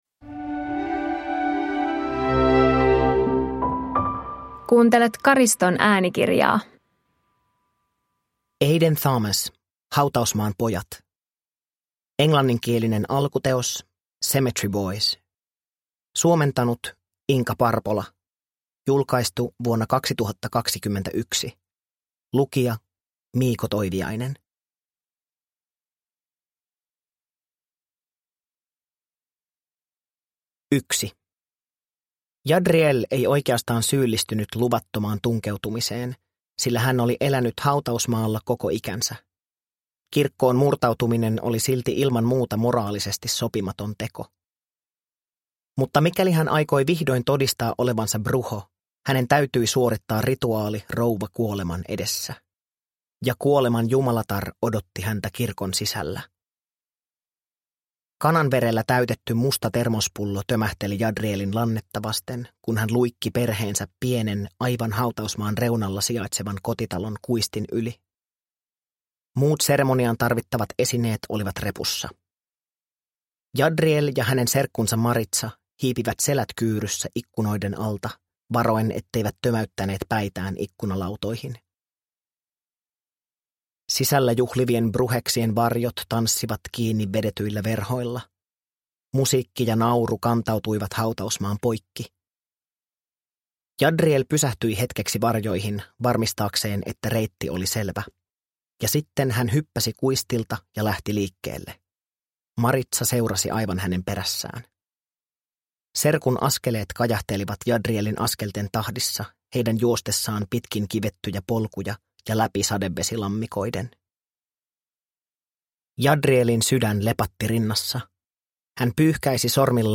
Hautausmaan pojat – Ljudbok – Laddas ner